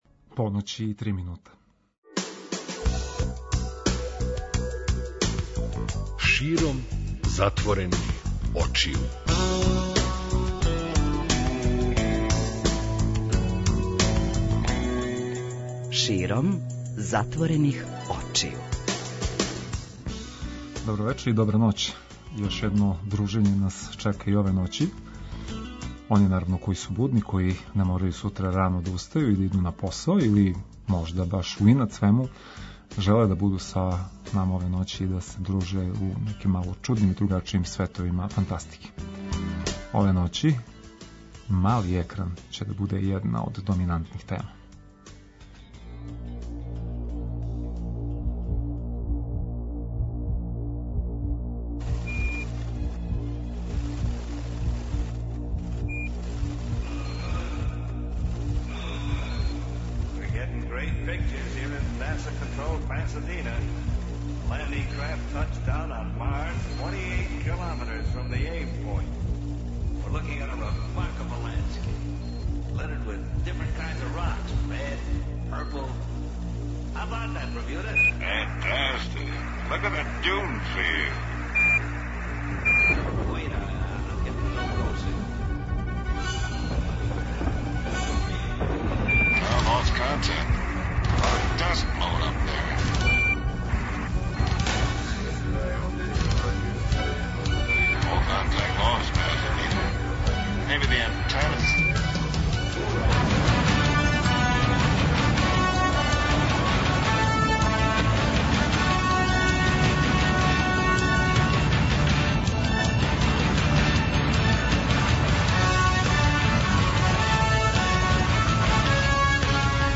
преузми : 55.21 MB Широм затворених очију Autor: Београд 202 Ноћни програм Београда 202 [ детаљније ] Све епизоде серијала Београд 202 Устанак Устанак Устанак Брза трака Брза трака: Млади у саобраћају